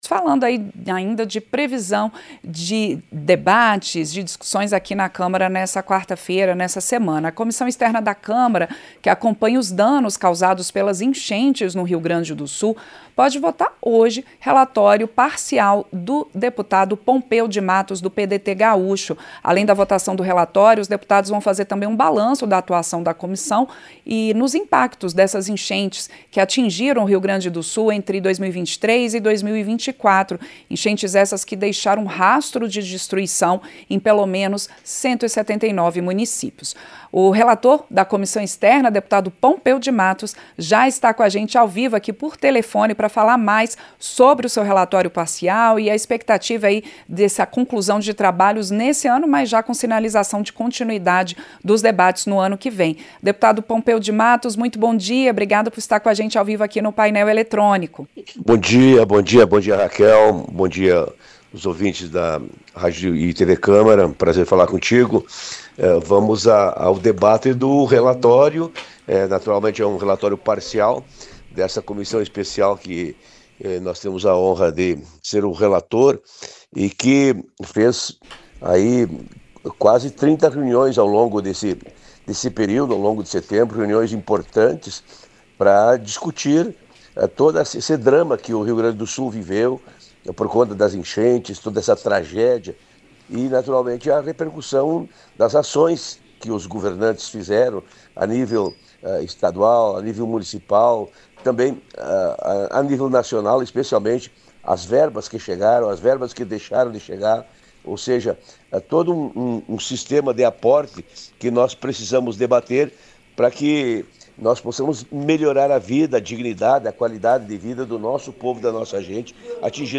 Entrevista - Dep. Pompeo Mattos (PDT-RS)